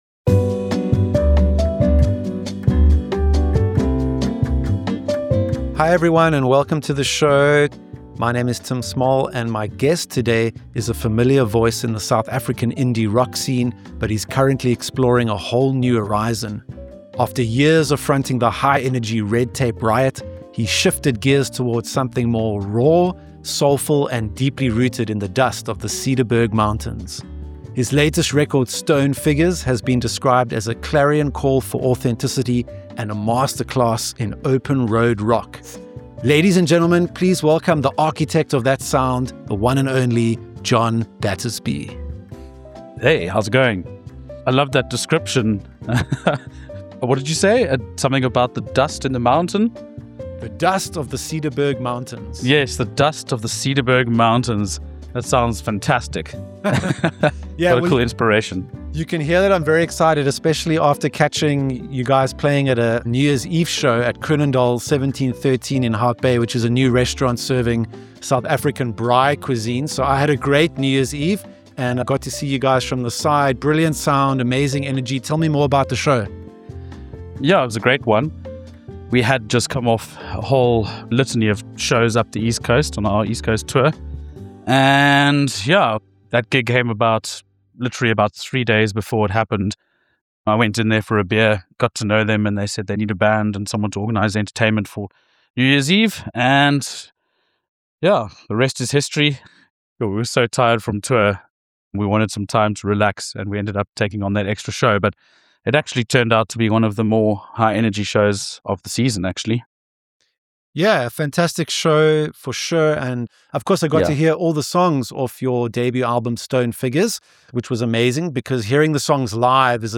Stone Figures [interview]